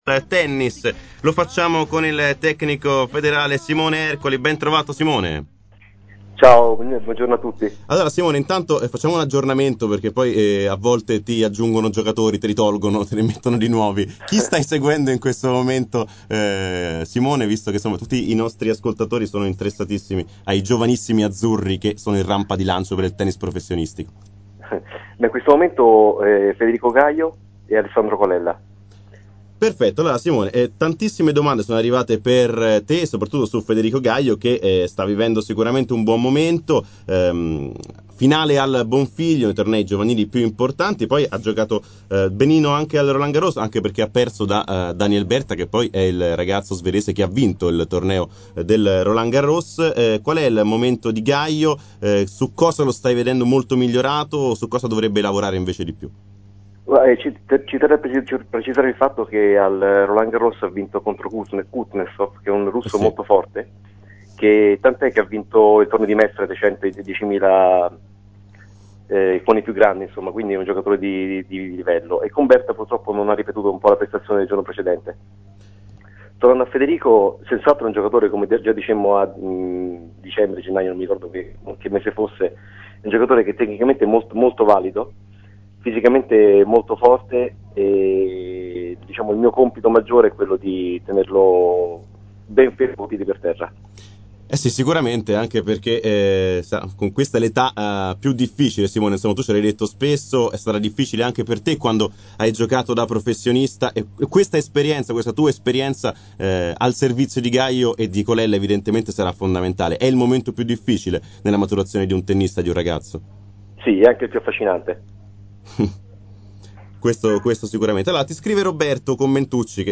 Intervista
intervistato durante la trasmissione Ho Scelto lo Sport su Nuova Spazio Radio